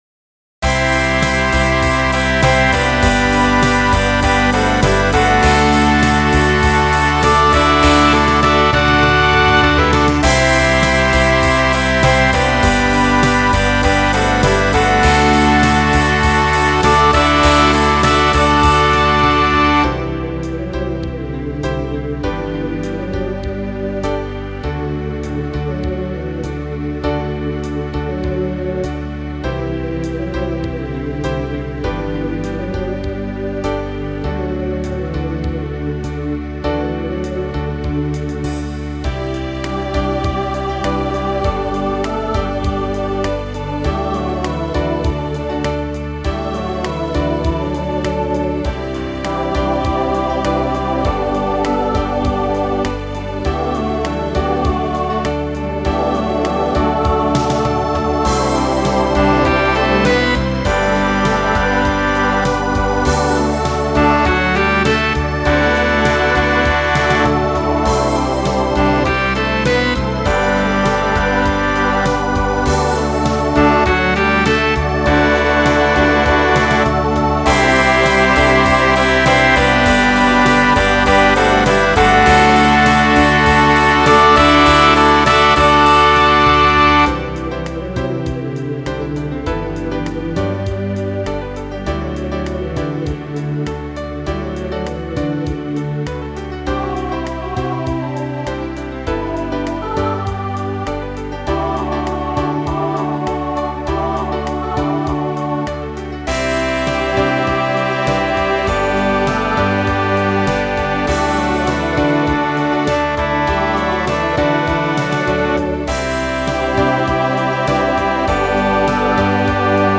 SATB Instrumental combo
Pop/Dance
Ballad